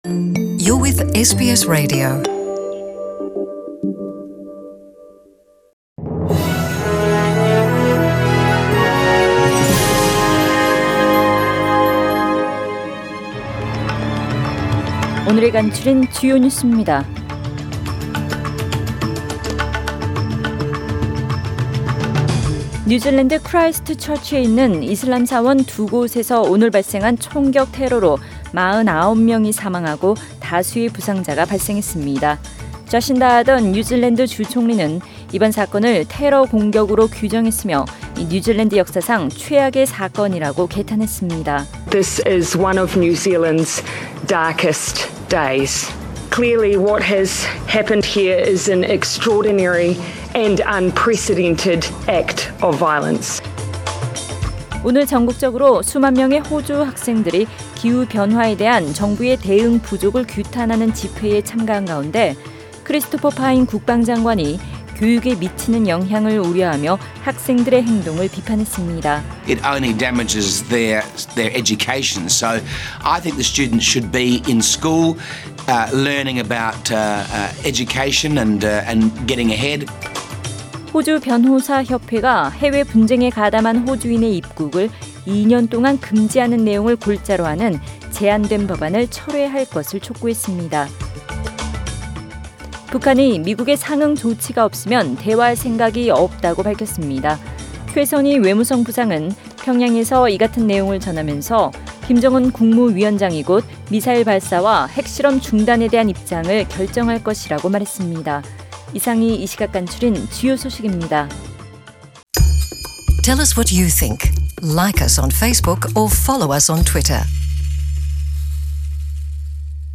SBS 한국어 뉴스 간추린 주요 소식 – 3월 15일 금요일
2019년 3월 15일 금요일 저녁의 SBS Radio 한국어 뉴스 간추린 주요 소식을 팟 캐스트를 통해 접하시기 바랍니다.